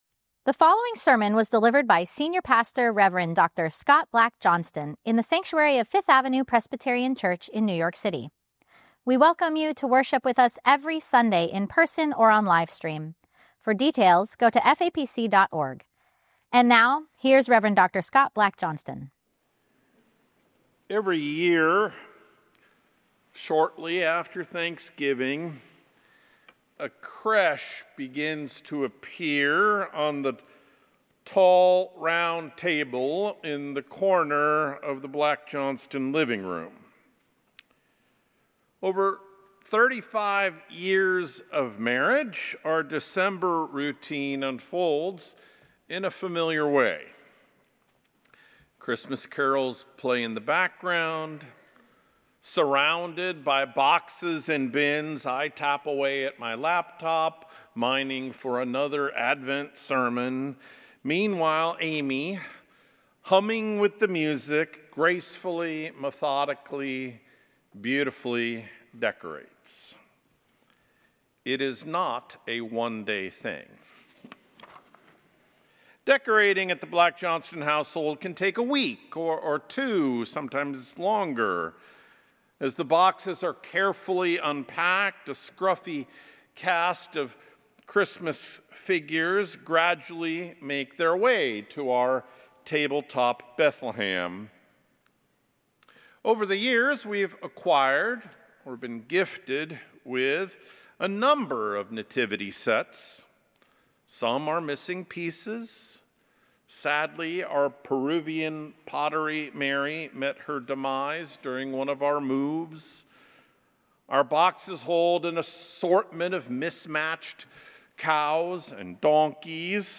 FAPC Sermon Series: Doorways to the Manger - Advent 2025